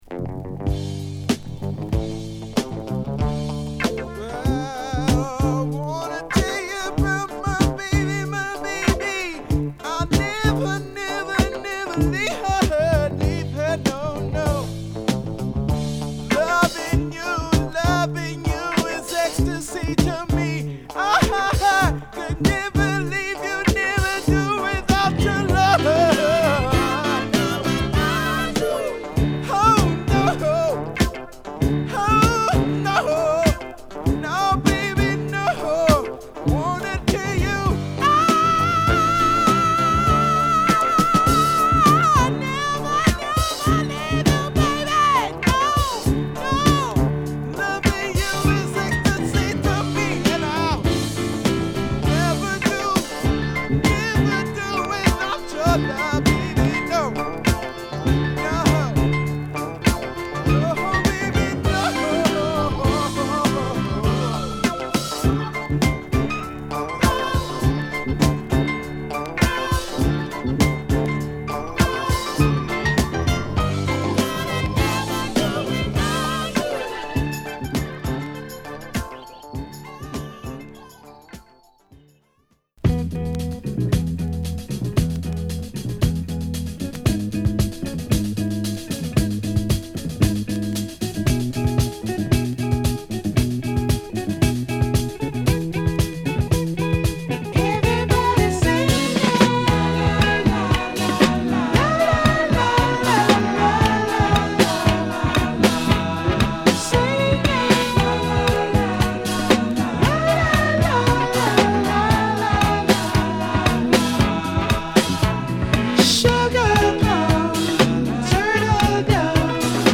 グッドソウル〜ファンクを満載！